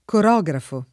[ kor 0g rafo ]